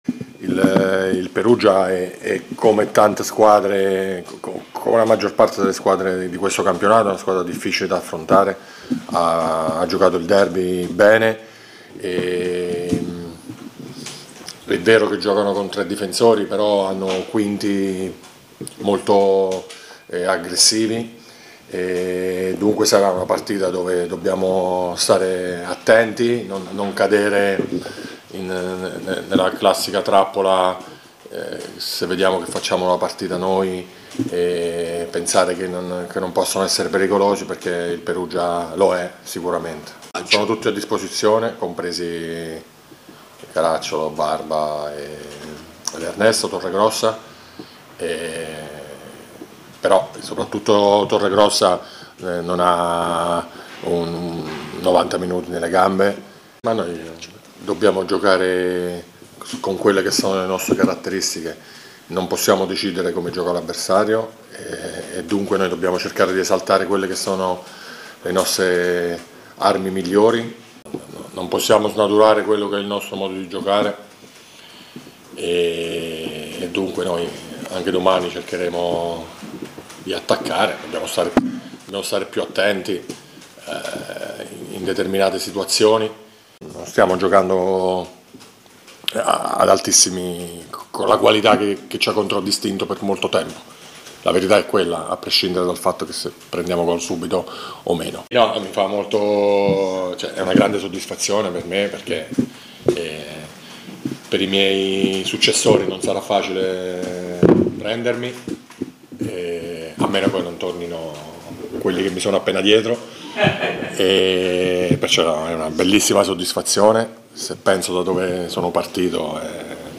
La conferenza